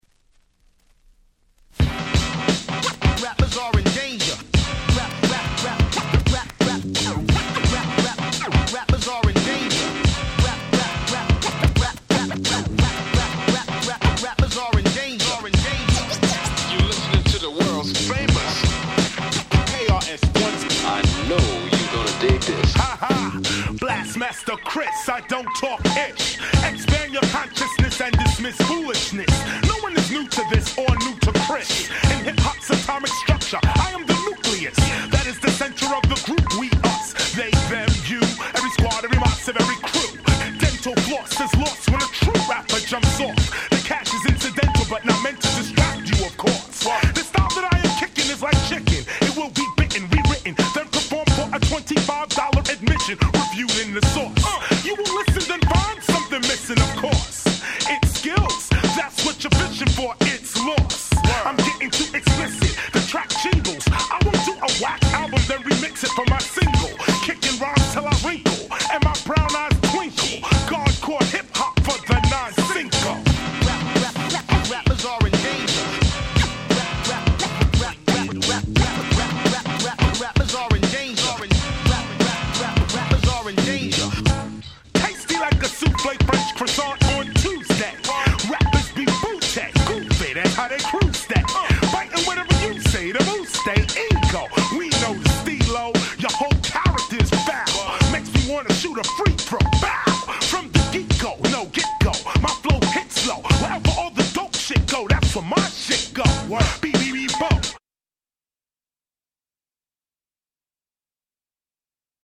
90's Hip Hop Classic !!